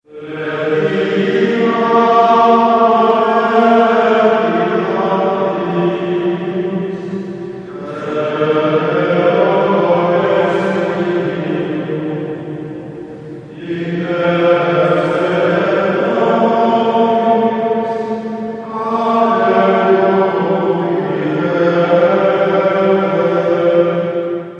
Monks - Песнь монахов